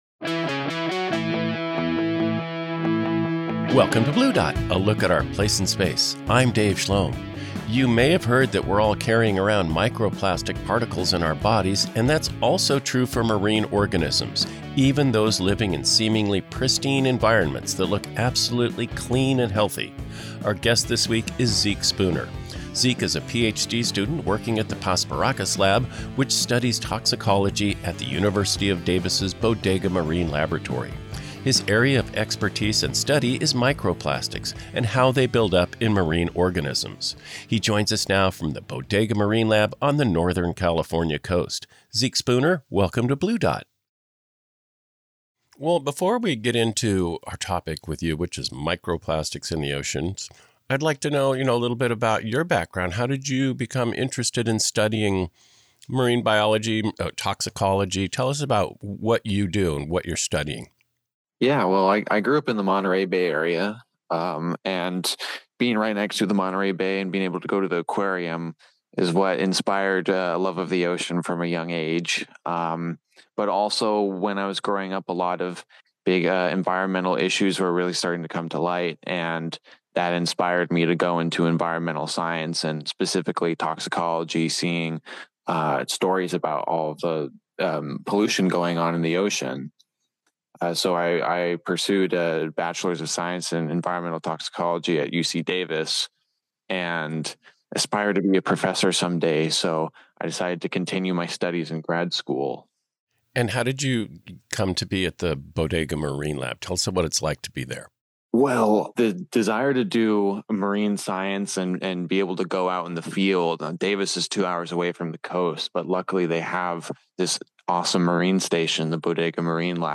Blue Dot, named after Carl Sagan's famous speech about our place in the universe, features interviews with guests from all over the regional, national and worldwide scientific communities.